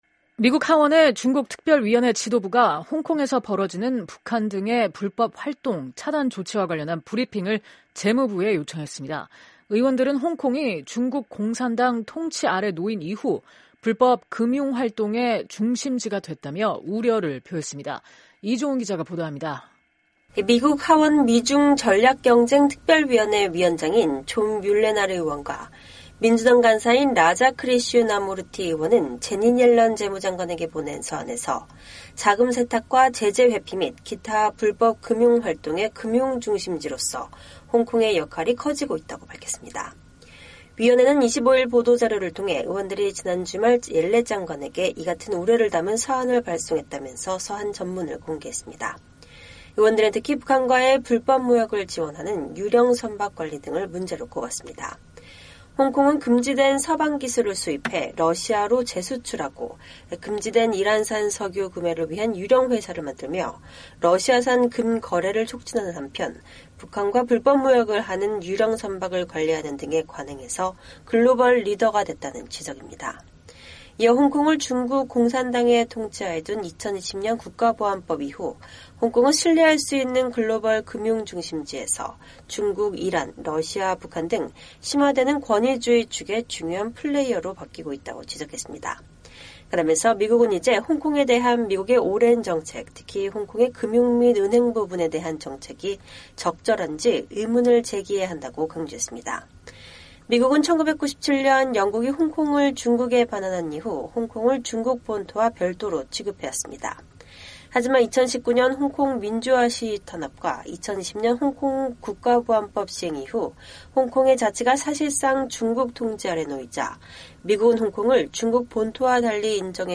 속보